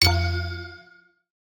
GS2_Item_Acquire_1.ogg